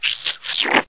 hanna-barbera-splats.mp3